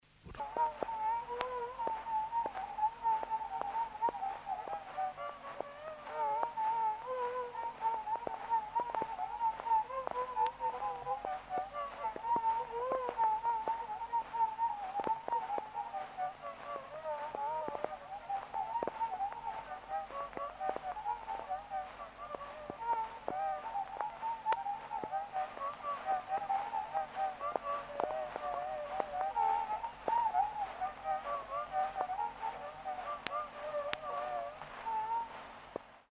Lyhyesti: kaikenlaista Etelä-Pohjanmaalla 1900-luvun alussa soitettua pelimannimusiikkia.
pariääniä suosivan soiton kulkua
Vihje äänitteen kuunteluun: kuuntele äänite useita kertoja nuotin kanssa, korva alkaa vähitellen tottua krahinaan ja soiton yksityiskohdat paljastuvat paremmin.
fonokop151_02_Klaasperkin-polska.mp3